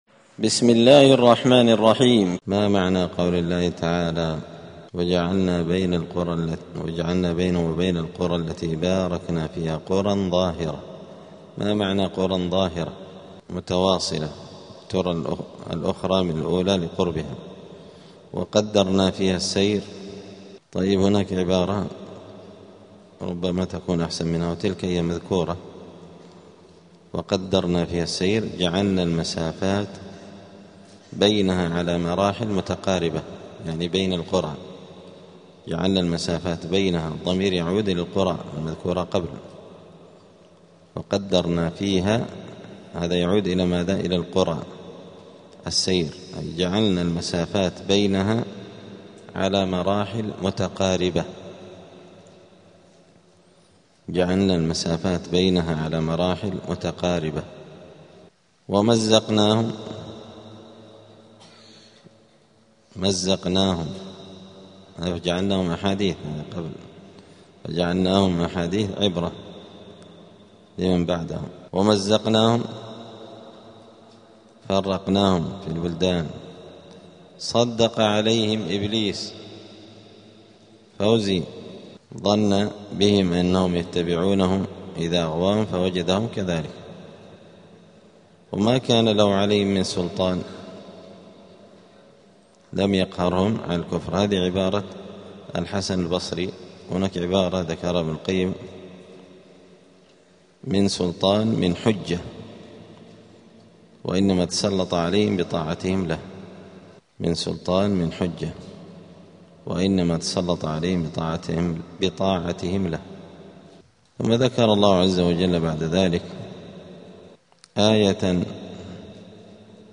الثلاثاء 8 شعبان 1447 هــــ | الدروس، دروس القران وعلومة، زبدة الأقوال في غريب كلام المتعال | شارك بتعليقك | 9 المشاهدات